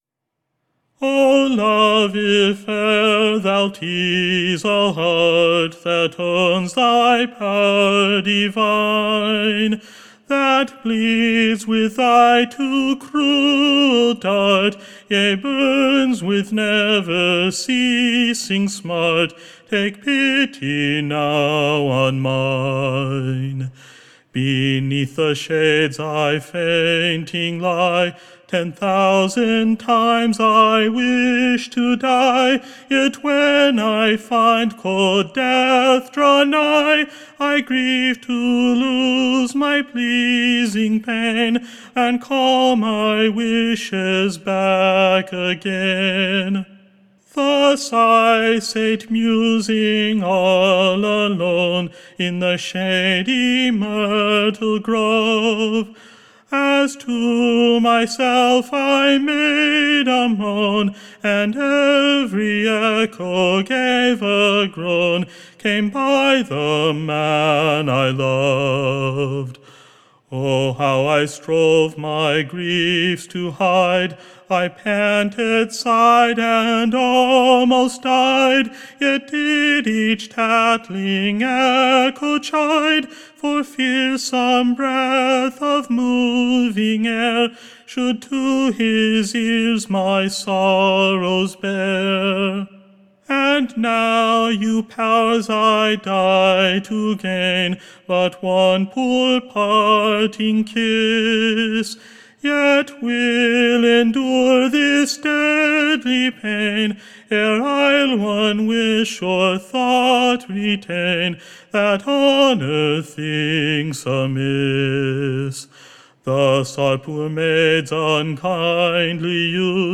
Recording Information Ballad Title The Tormented Lovers.